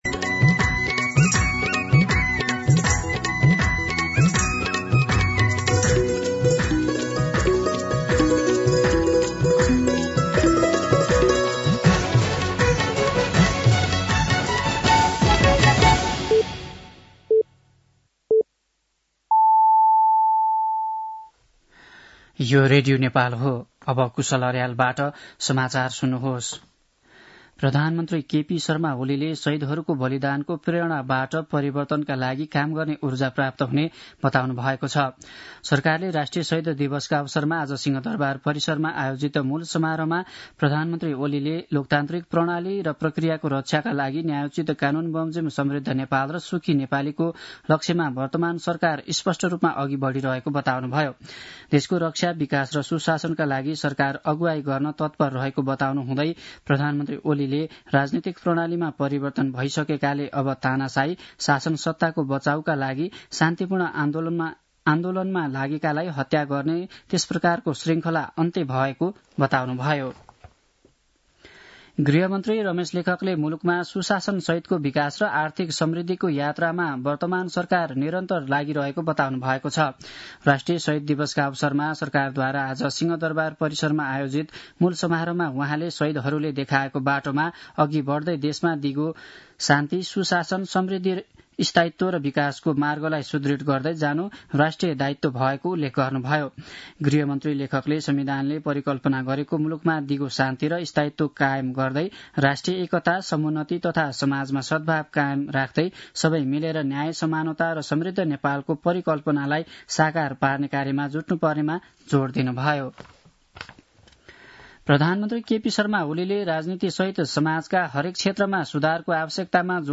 साँझ ५ बजेको नेपाली समाचार : १७ माघ , २०८१
5-PM-Nepali-News-10-16.mp3